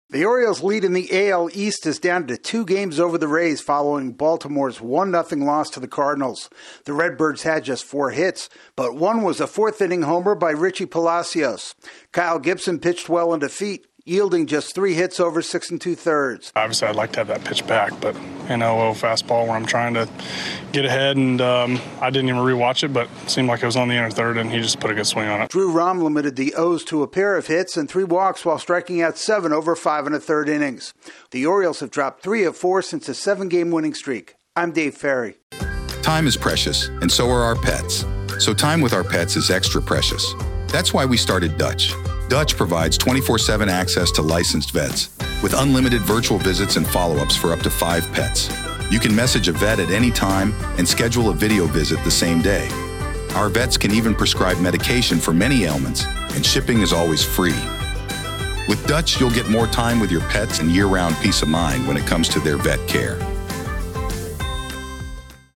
The Orioles fall to the Cardinals for the second straight day. AP correspondent